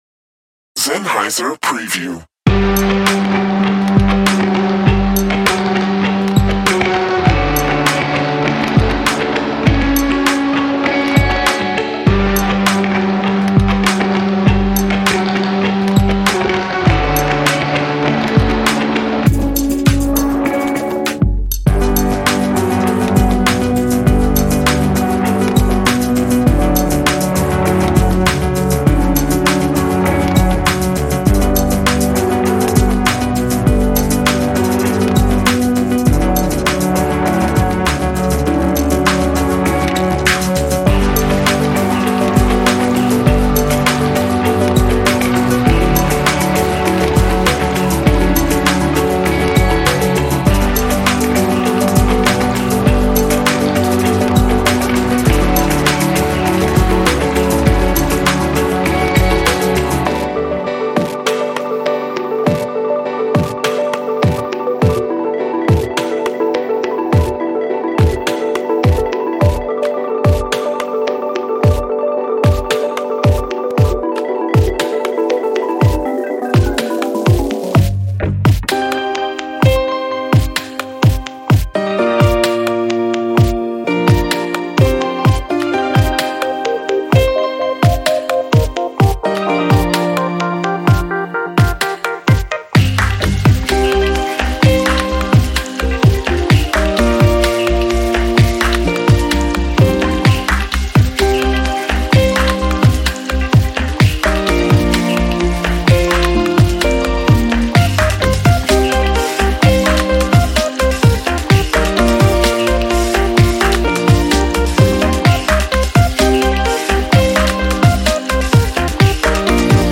Dip your feet in into the 1.1GB collection and be instantly immersed in a parallel world where live instruments meet foley loops, organic beats compliment soft sounds.
By processing, re-sampling, using tapes and recording the end result through different room ambiances this collection has become an instant classic for downtempo producers and electronica artists alike.